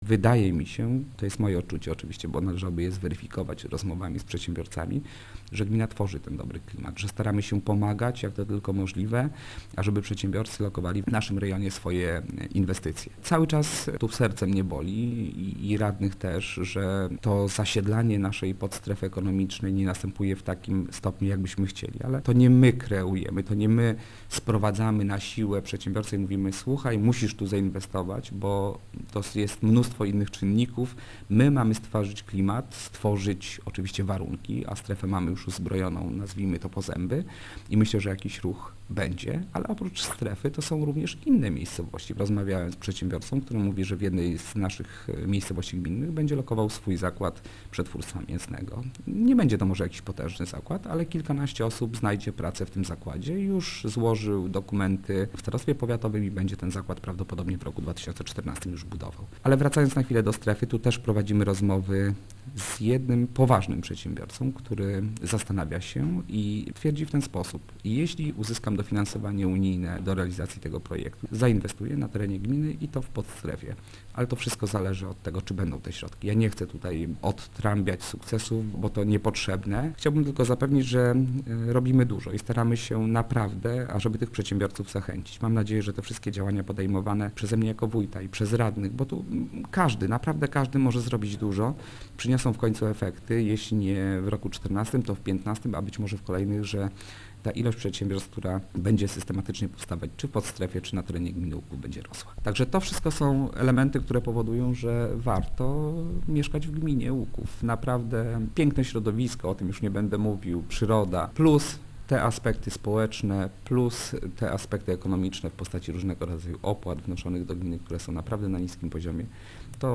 Wójt Mariusz Osiak przyznaje, że jednym z najważniejszych wyzwań jest pozyskiwanie inwestorów, ale jednocześnie przypomina, że gmina nie tworzy miejsc pracy, a jedynie dba o dobry klimat dla potencjalnych inwestorów: